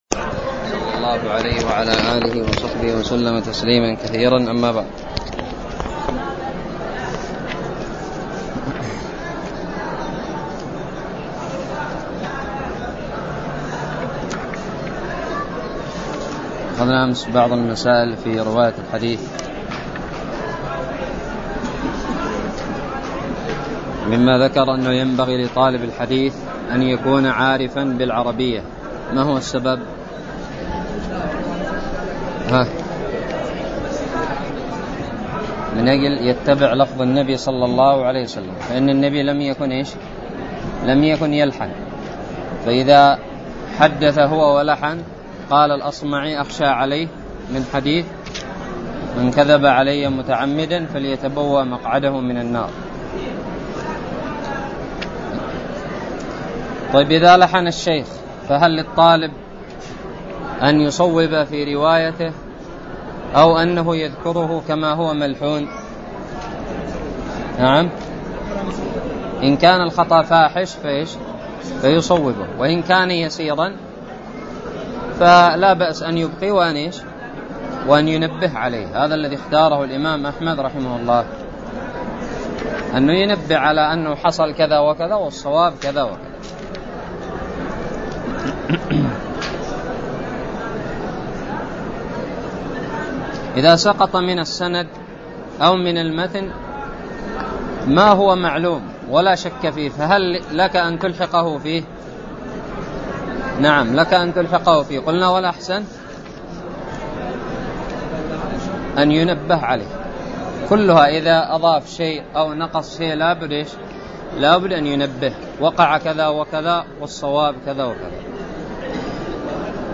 الدرس التاسع والثلاثون من شرح كتاب الباعث الحثيث
ألقيت بدار الحديث السلفية للعلوم الشرعية بالضالع